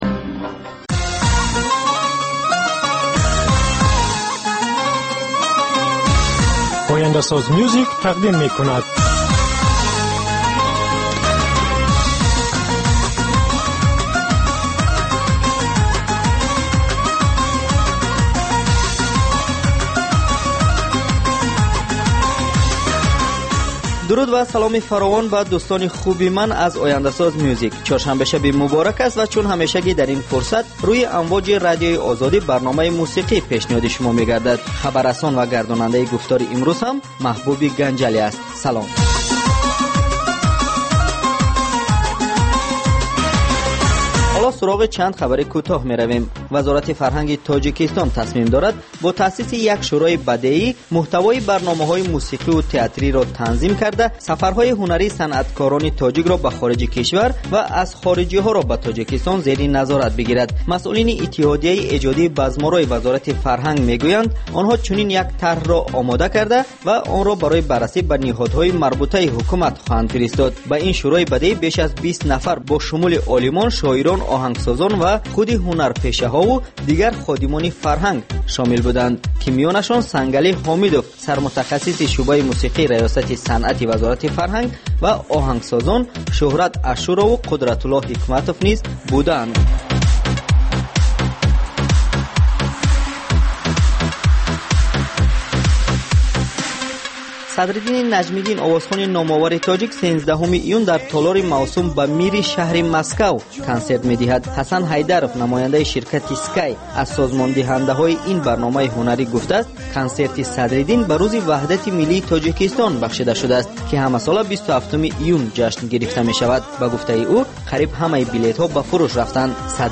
Навгониҳои мусиқӣ, беҳтарин оҳангҳо, гуфтугӯ бо оҳангсозон, овозхонон ва бинандагон, гузориш аз консертҳо ва маҳфилҳои ҳунарӣ.